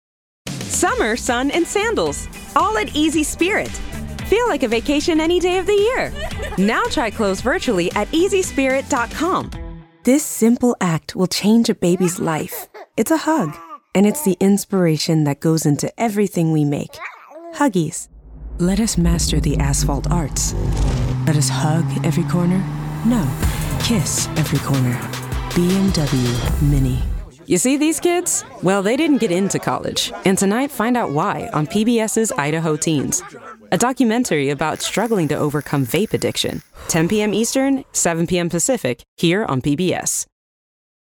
Browse our catalog of professional voice actor demos recorded, mixed, and produced in-house at Edge Studio NYC.
Genre: Commercial